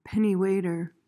PRONUNCIATION:
(PEN-ee-way-tuhr)